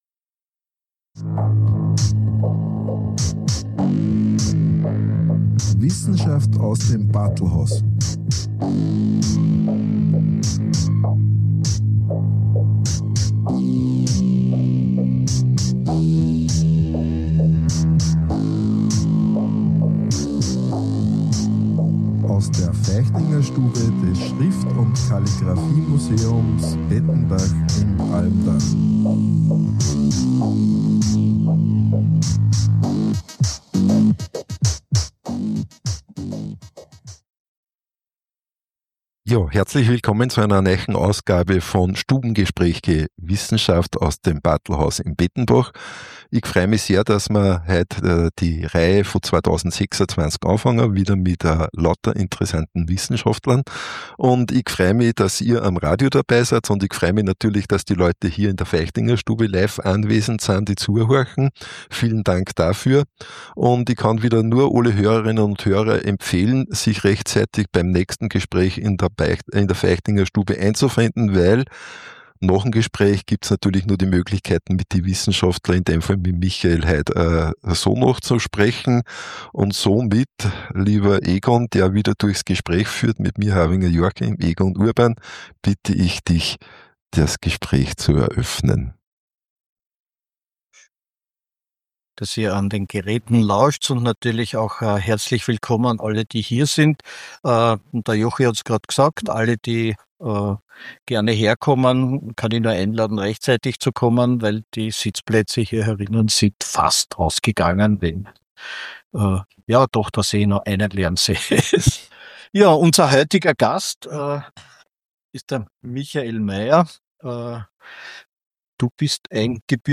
Stubengespräch Wissenschaft
Im Bartlhaus z’Pettenbach, Feichtingerstube